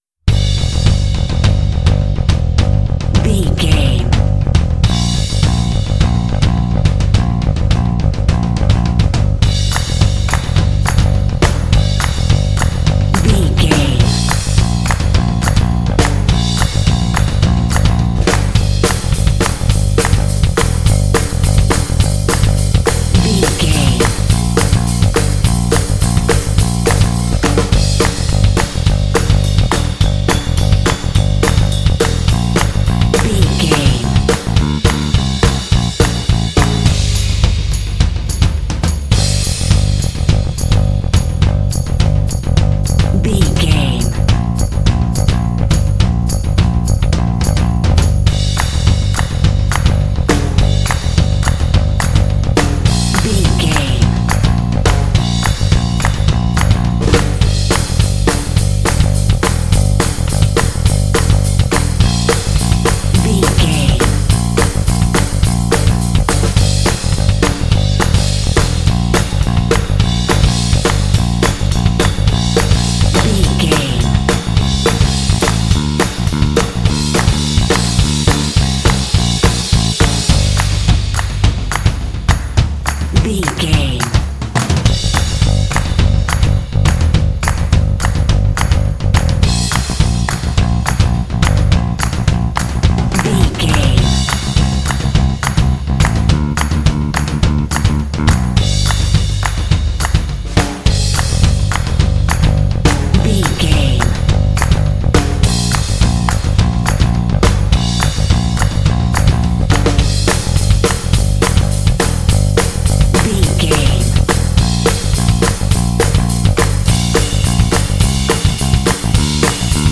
Fast paced
Aeolian/Minor
Fast
energetic
dark
groovy
funky
drums
bass guitar
synth-pop
new wave